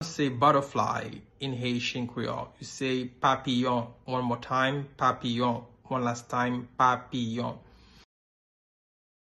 Pronunciation:
4.How-to-say-Butterfly-in-Haitian-Creole-–-Papiyon-pronunciation-.mp3